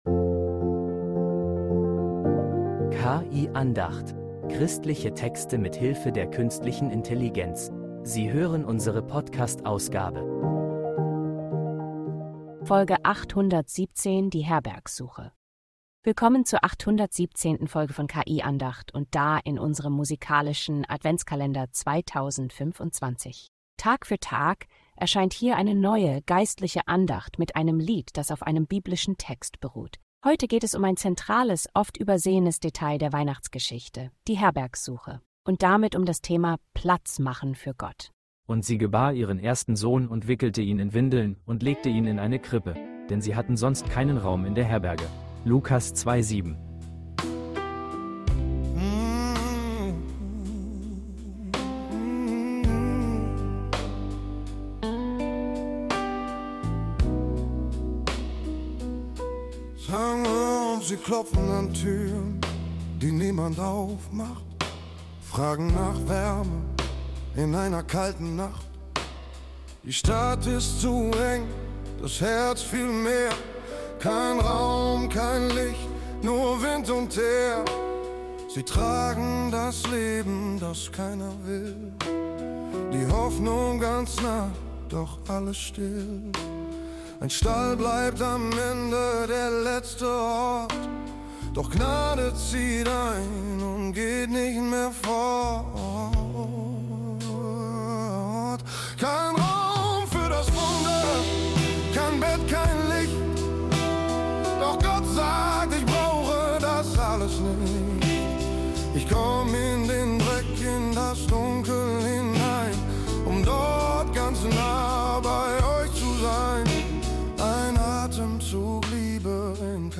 Christliche Texte mit Hilfe der Künstlichen Intelligenz
Geschichte als bluesiges Lied voller Sehnsucht und Würde.